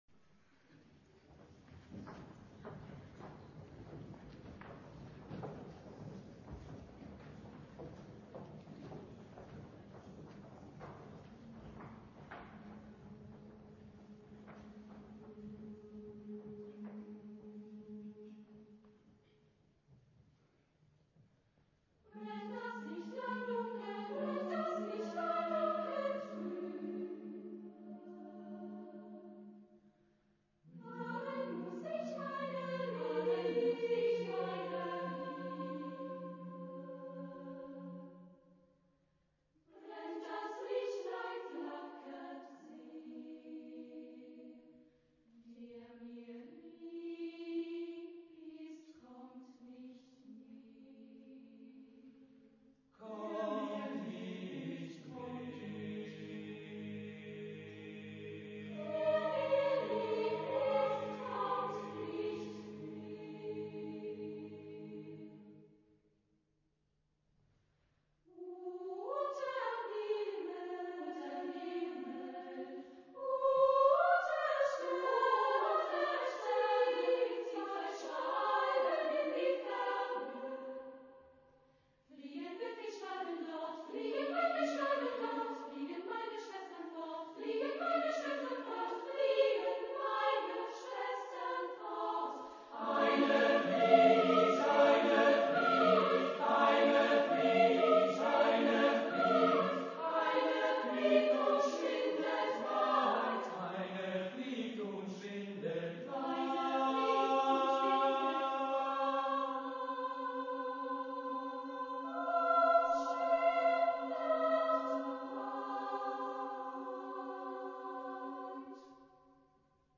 SAATBB (6 voix mixtes) ; Partition complète.
Pièce chorale.
Genre-Style-Forme : Cycle ; Pièce chorale ; Profane
Tonalité : dodécaphonique ; libre